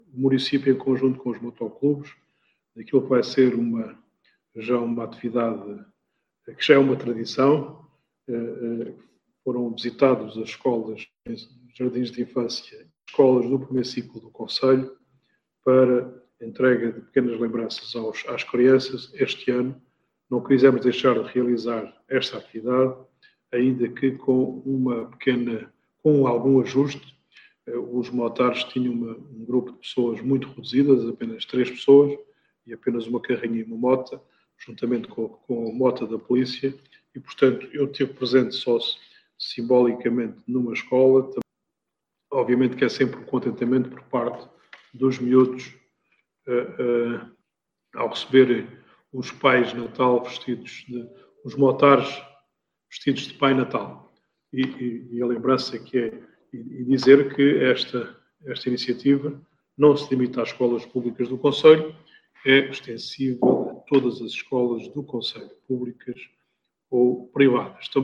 Áudio: Presidente da Câmara Municipal do Entroncamento em reunião de Câmara a 21 de dezembro, onde fala sobre esta visita às escolas